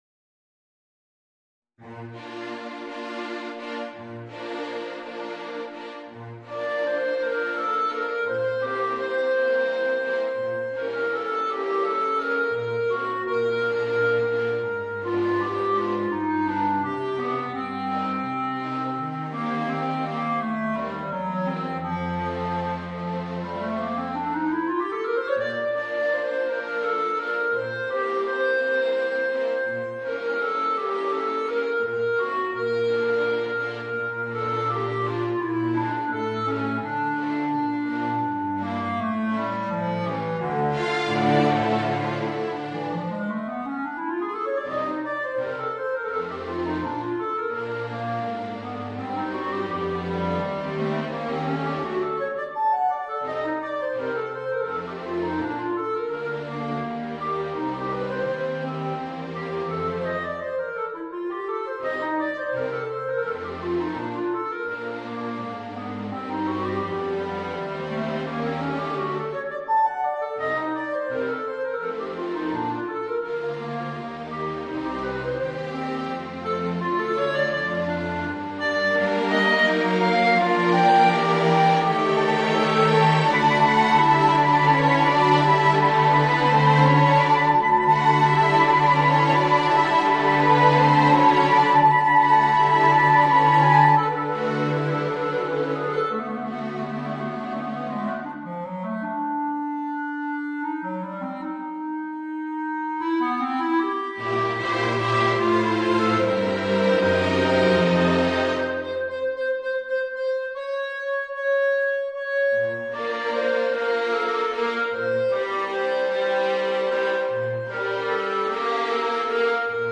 Voicing: Clarinet and String Quartet